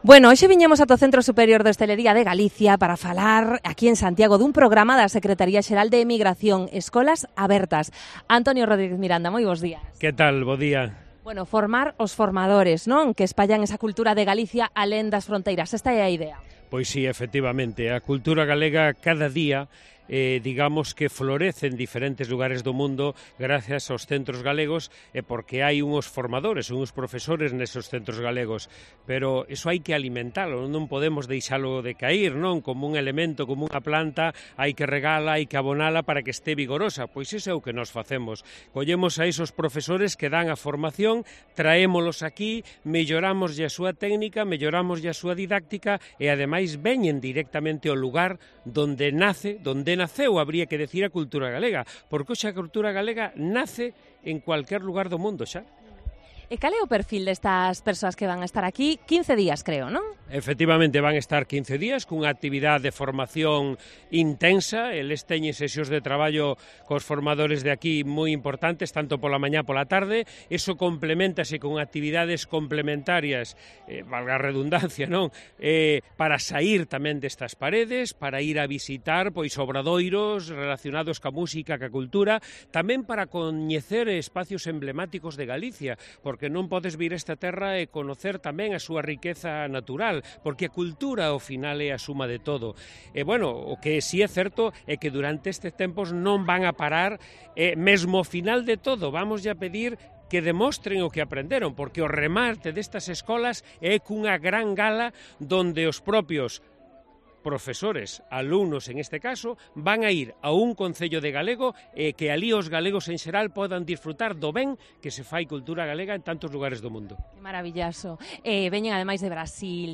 El secretario xeral de Emigración de la Xunta de Galicia nos explica la iniciativa Escolas abertas